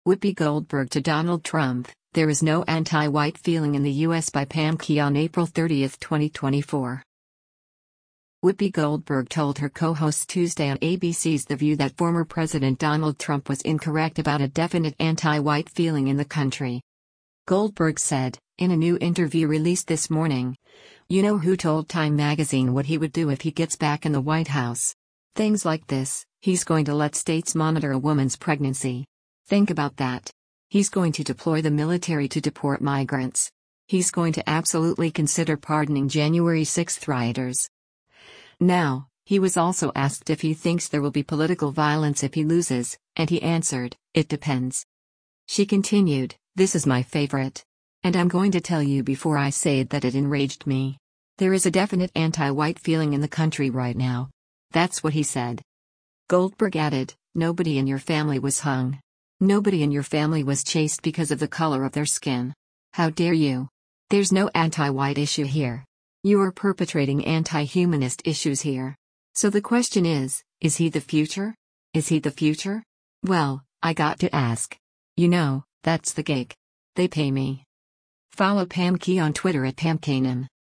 Whoopi Goldberg told her co-hosts Tuesday on ABC’s “The View” that former President Donald Trump was incorrect about a “definite anti-white feeling in the country.”